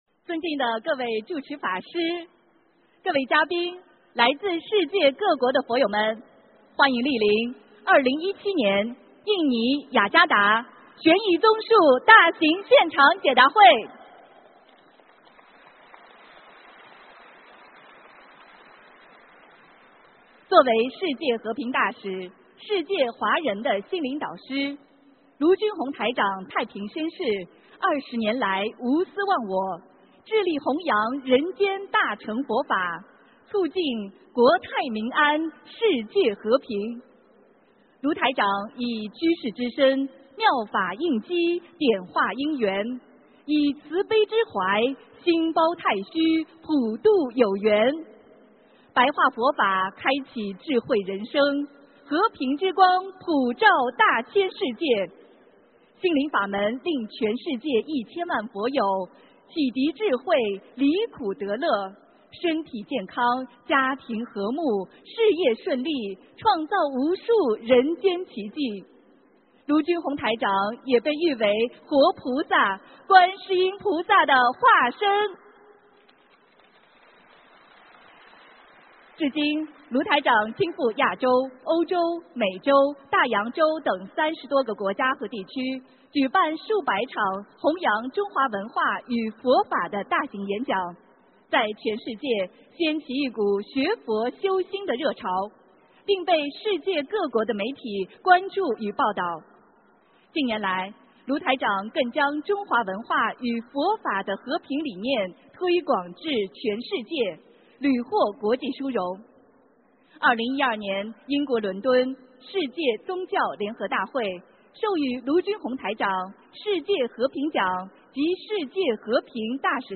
【2017印度尼西亚·雅加达】4月23日 大法会 文字+音频 - 2017法会合集 (全) 慈悲妙音